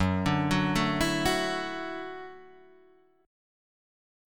Gbm7#5 chord